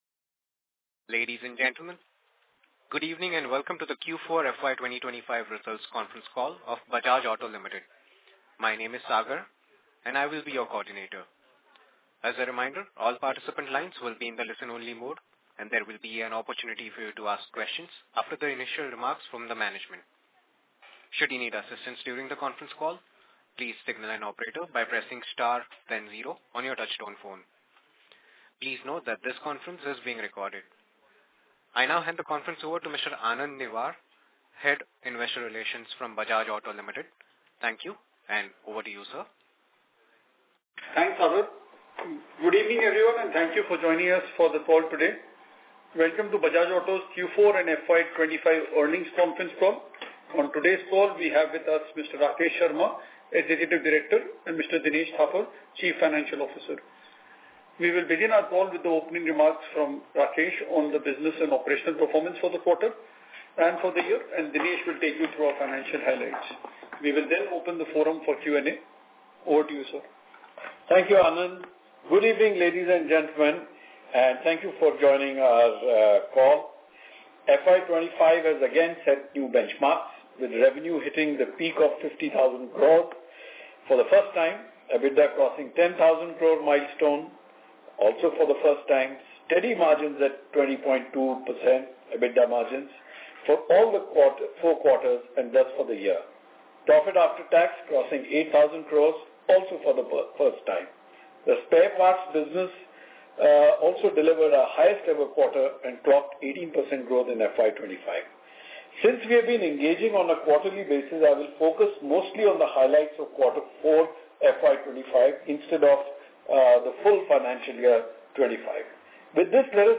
Concalls
earnings-call-audio-recording-q4-fy25.mp3